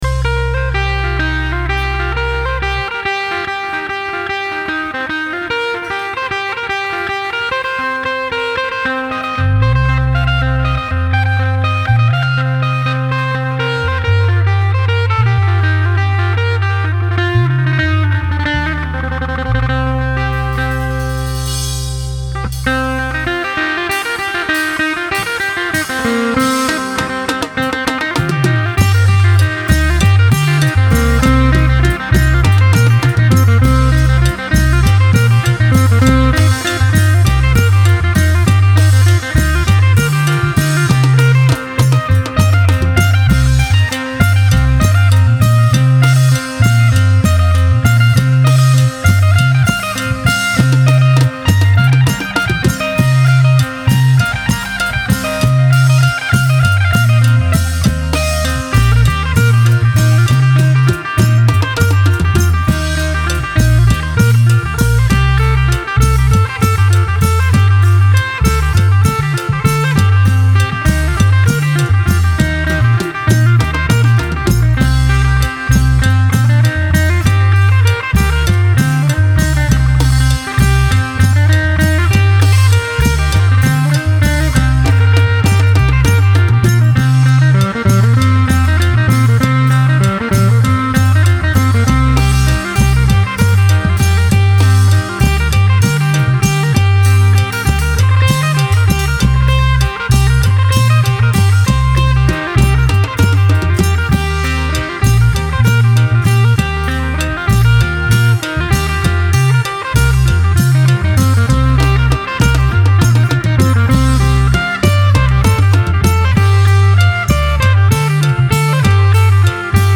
Genre: World, Tai